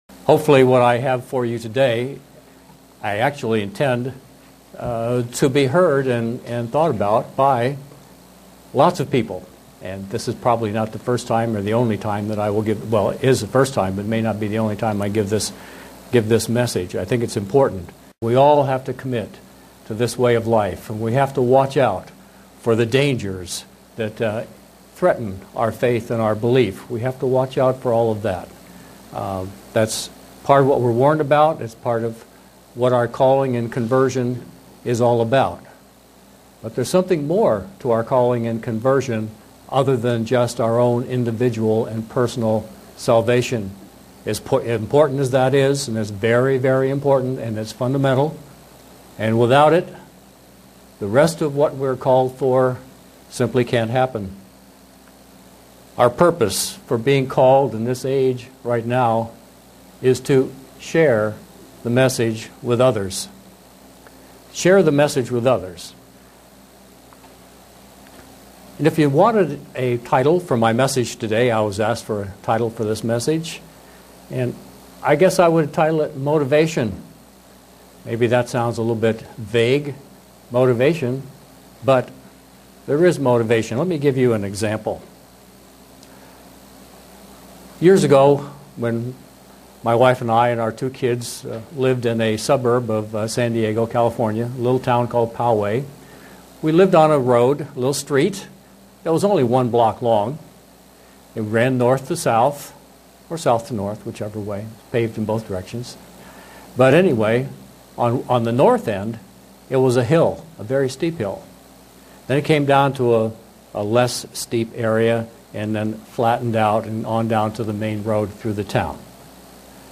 Print Be motivated to share with others the end time events that will lead to Christs' Kingdom on earth. sermon Studying the bible?